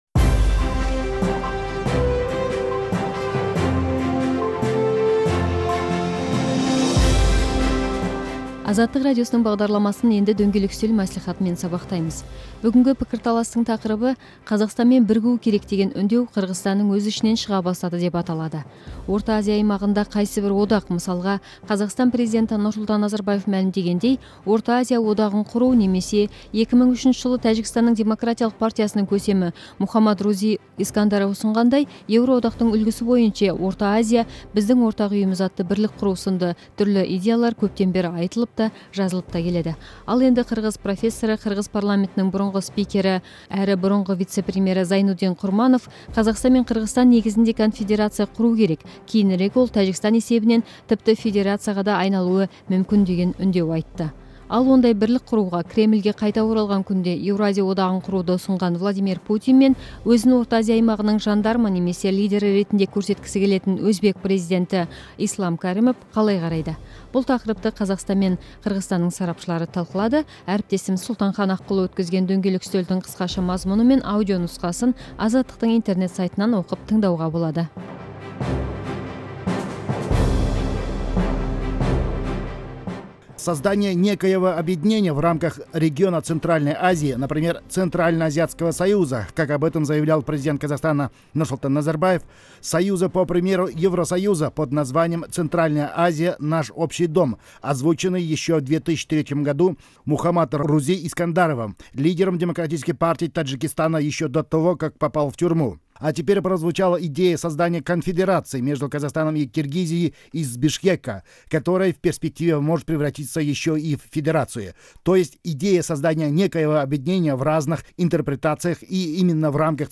Запись круглого стола